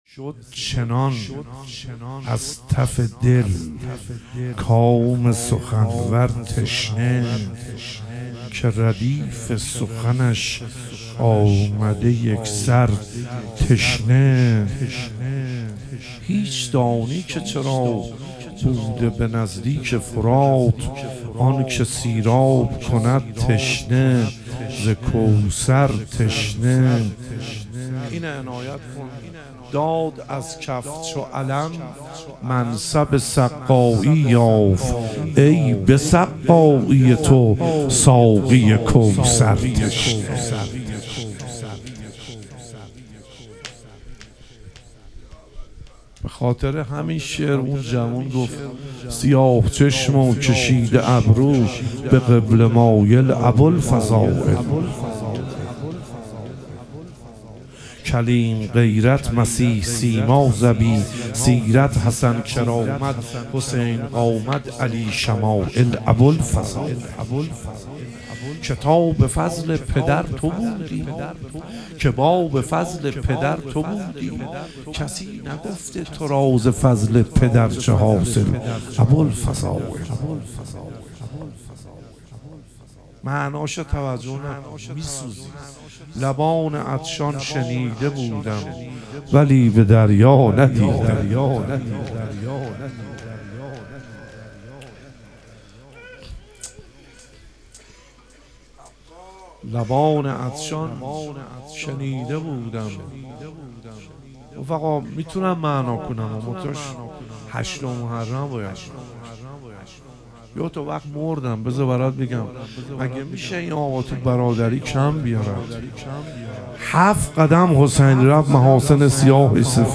ظهور وجود مقدس حضرت علی اکبر علیه السلام - روضه